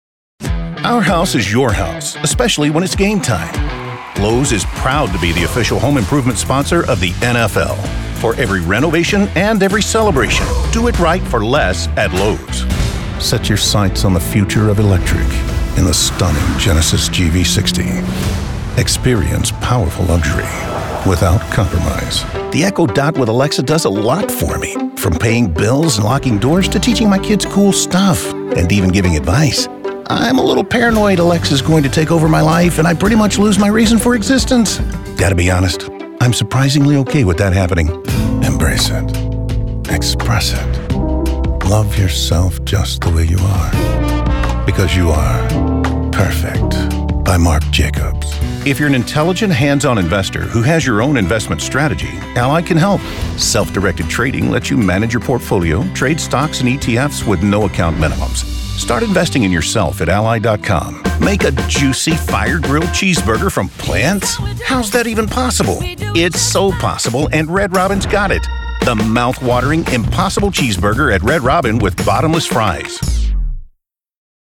Native speaker Male 50 lat +